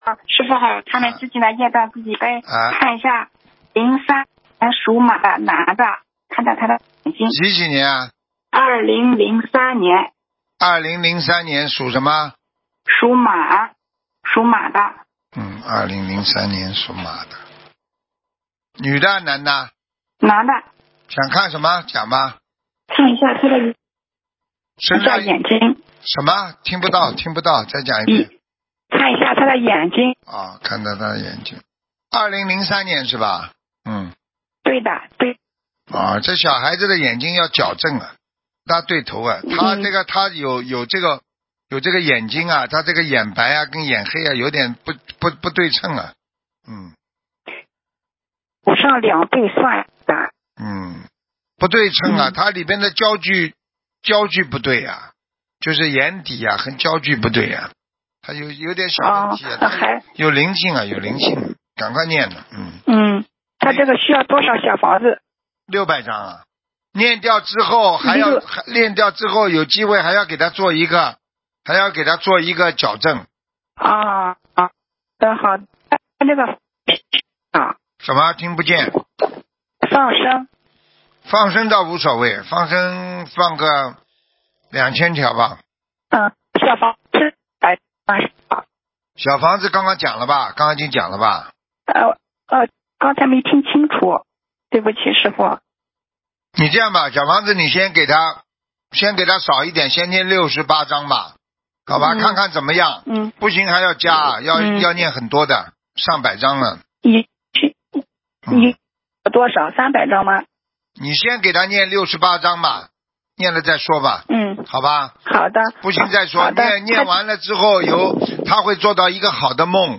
目录：2019年12月_剪辑电台节目录音_集锦